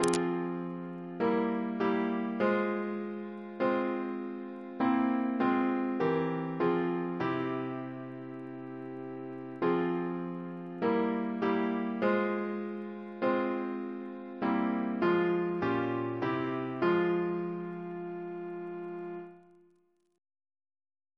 Double chant in F minor Composer: Richard P. Goodenough (1775-1826) Reference psalters: OCB: 116